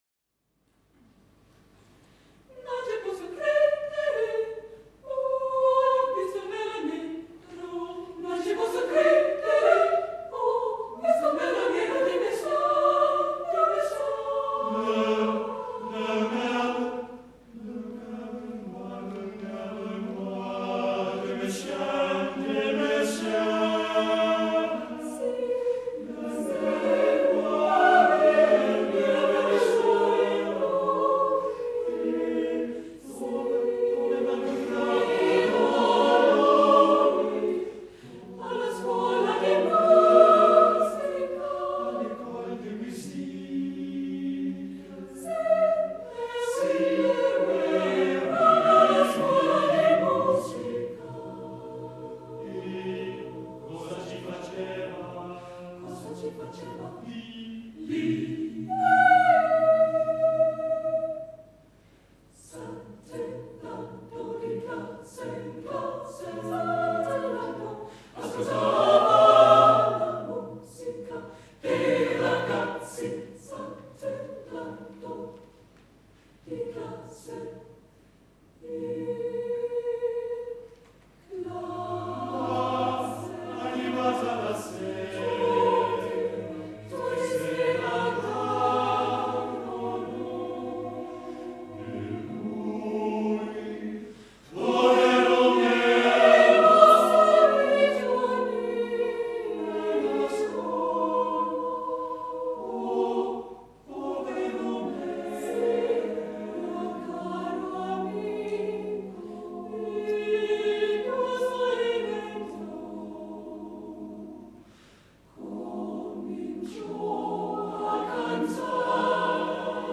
Number of voices: 4vv Voicing: SATB Genre: Secular, Madrigal
Language: Italian Instruments: A cappella